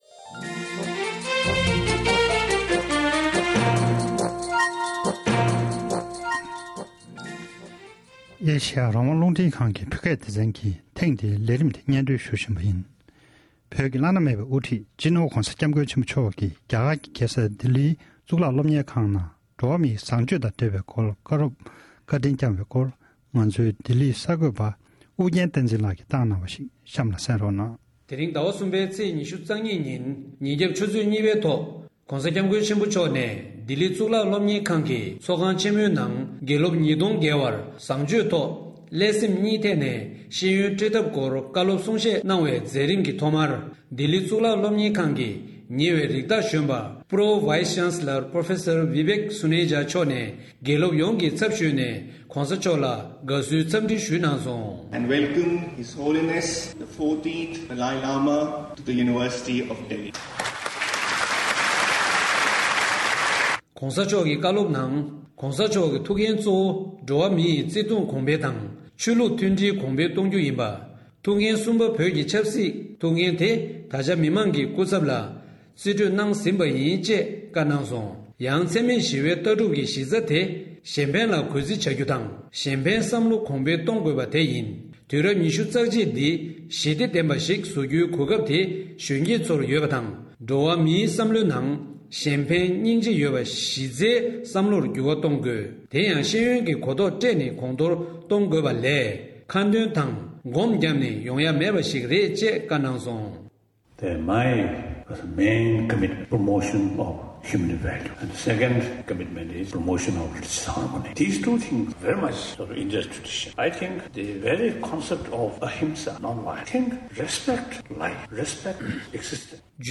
༸གོང་ས་མཆོག་གིས་ལྡི་ལི་གཙུག་ལག་སློབ་གྲྭ་ཆེན་མོའི་ཚོགས་ཁང་དུ་དགེ་སློབ་༢༠༠༠བརྒལ་བར་བཟང་སྤྱོད་ཐོག་བཀའ་སློབ་སྩོལ་བཞིན་པ།
སྒྲ་ལྡན་གསར་འགྱུར། སྒྲ་ཕབ་ལེན།